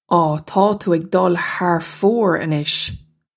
Pronunciation for how to say
Aw, taw too ig dull har foar a-nish!
This is an approximate phonetic pronunciation of the phrase.